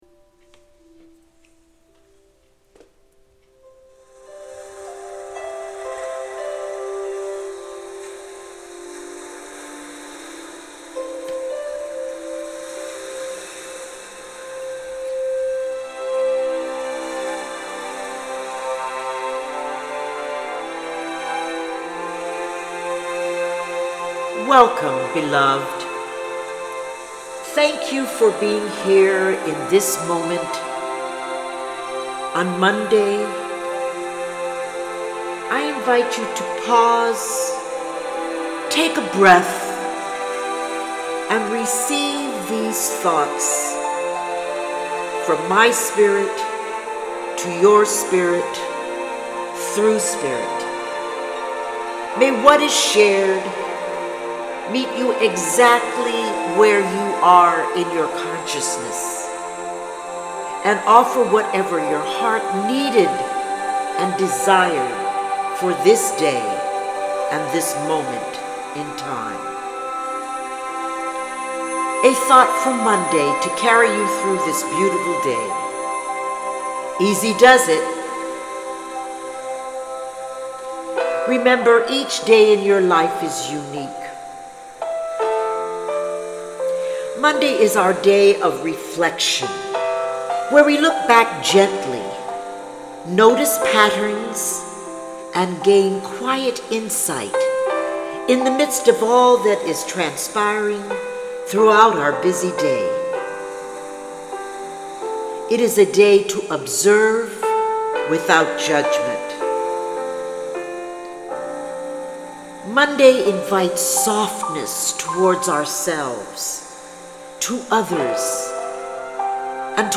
The recordings are a reflection of the writings and are not edited because I do not want to alter what is coming to me through “Great Spirit”.
Thank You Jim Brickman for your beautiful music that vibrates through this recording.